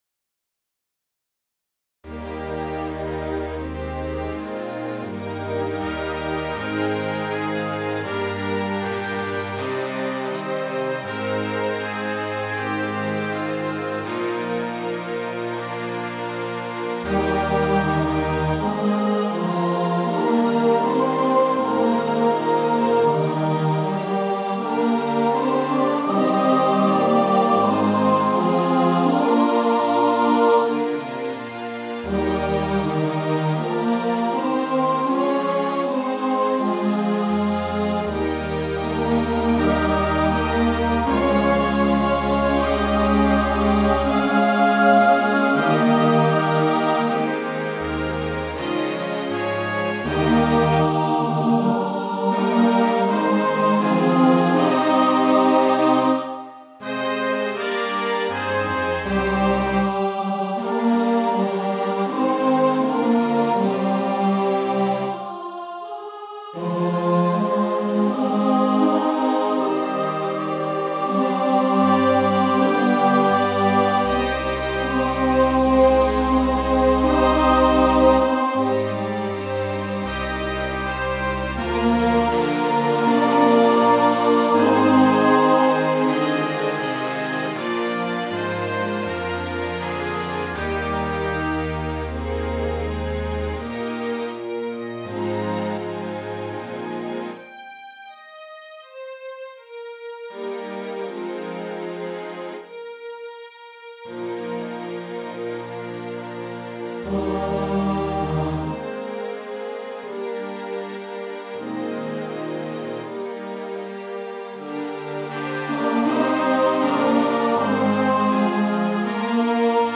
• Music Type: Instrumental Parts
• Accompaniment: Trombone, Trumpet
A large anthem that can be done with additional brass